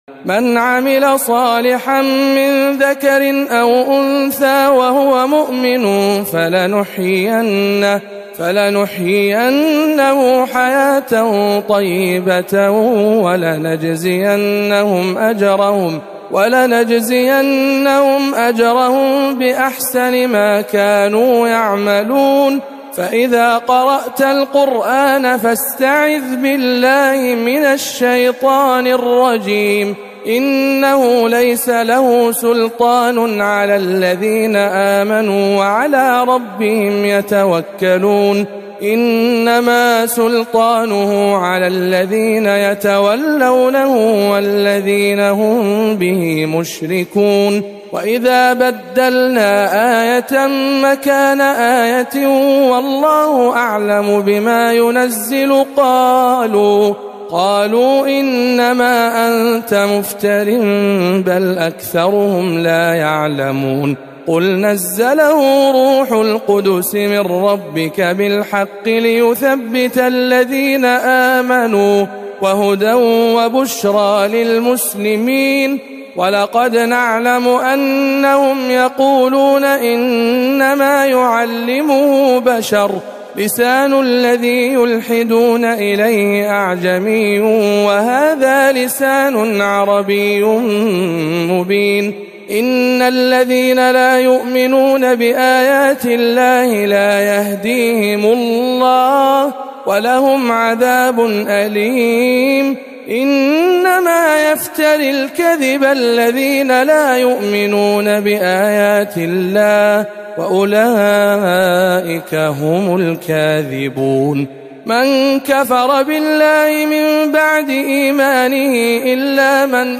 تلاوة مميزة من سورة النحل